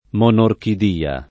[ m q norkid & a ]